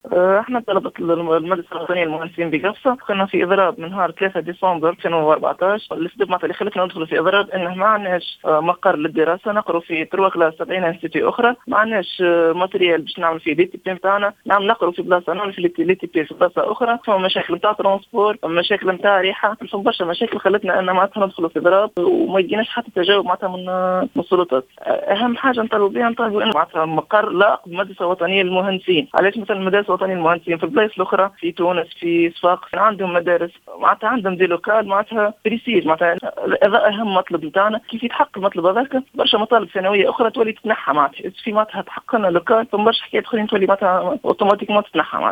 وأكد أحد الطلبة المضربين في تصريح لجوهرة "اف ام" ان مطالبهم تتمثل في توفير مقر لهم على غرار مدارس المهندسين في بقية الولايات اضافة الى حل مشاكل التنقل التي يعانون منها مؤكدا أنهم طرحوا هذه المطالب في أكثر من مناسبة ولكن تم تجاهلهم من قبل السلط المعنية .